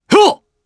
Roman-Vox_Attack3_jp.wav